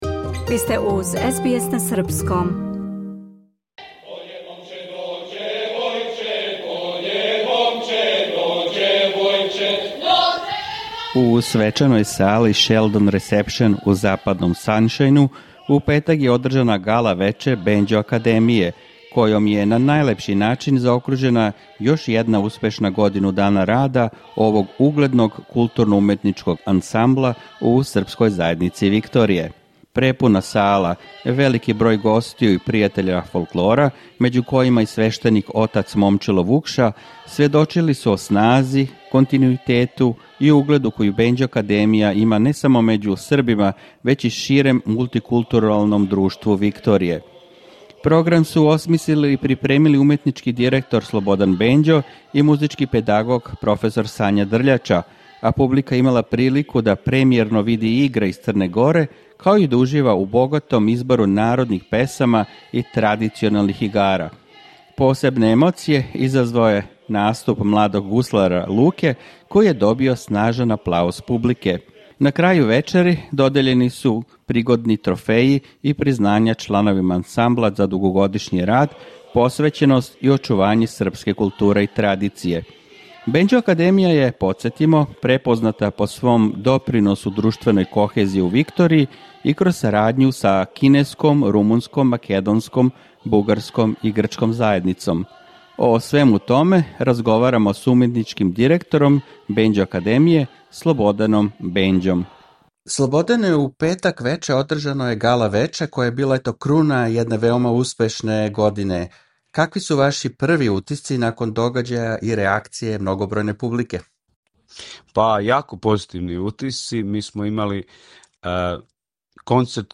разговорао